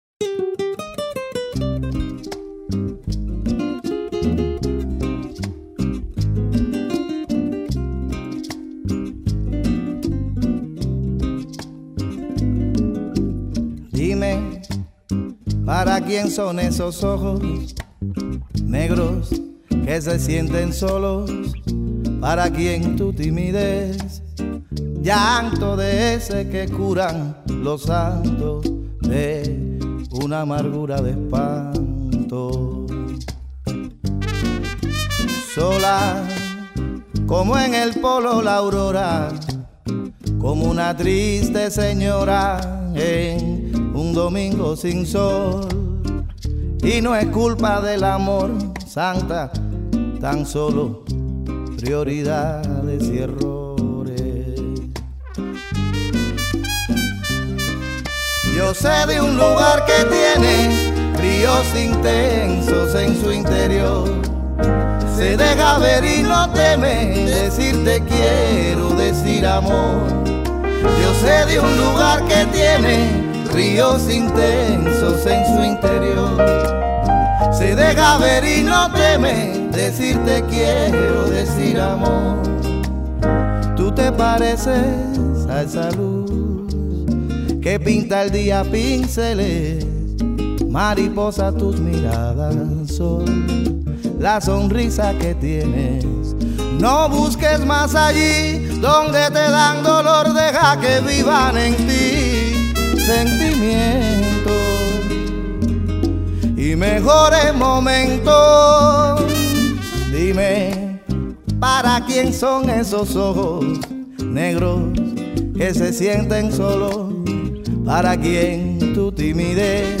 исполненную в дуэте